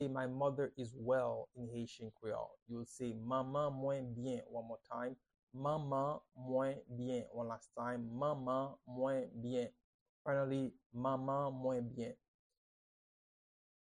Pronunciation and Transcript:
My-mother-is-well-in-Haitian-Creole-Manman-mwen-byen-pronunciation-by-a-Haitian-teacher.mp3